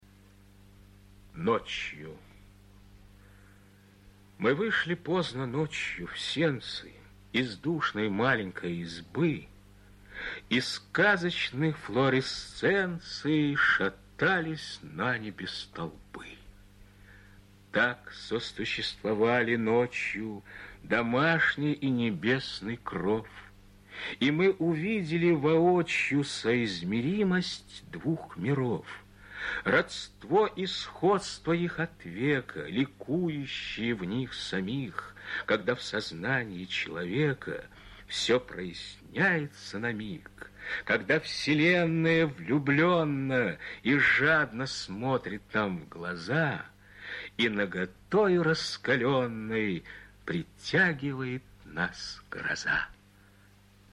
Ночью (стих.чит.